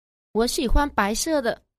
ủa xỉ hoan pái xưa tợ.